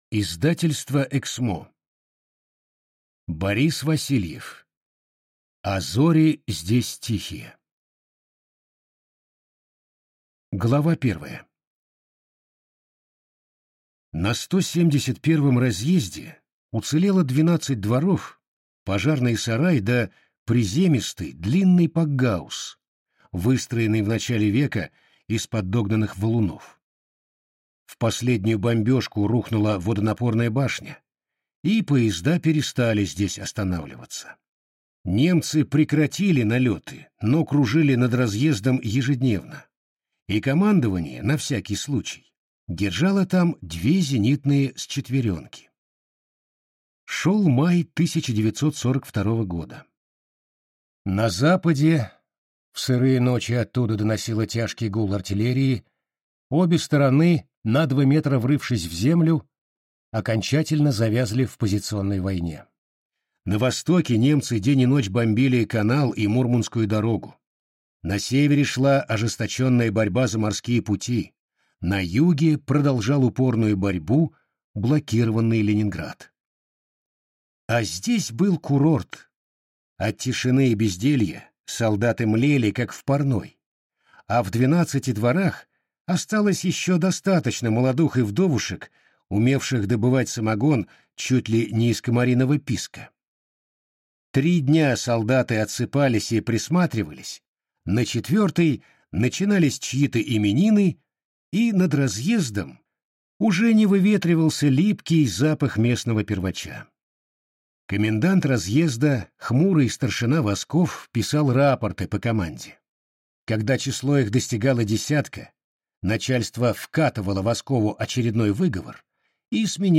Аудиокнига А зори здесь тихие…